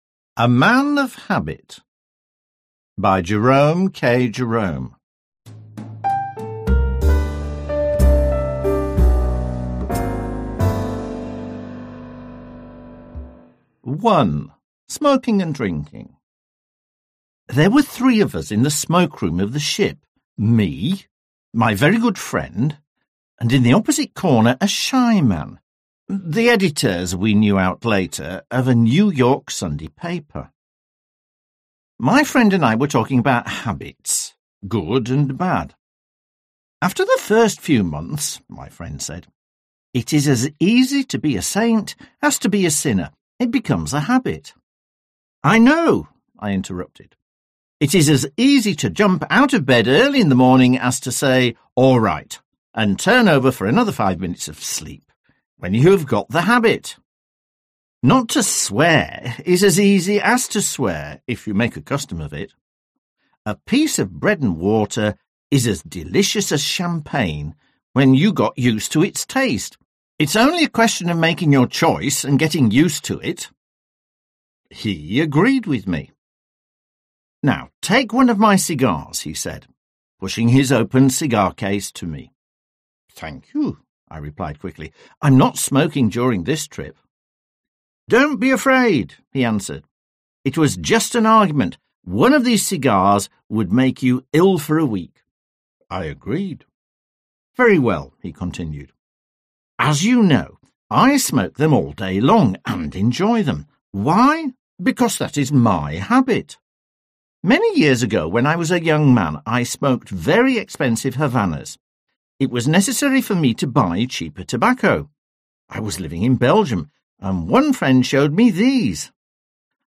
Аудиокнига Самые смешные рассказы / The Best Funny Stories | Библиотека аудиокниг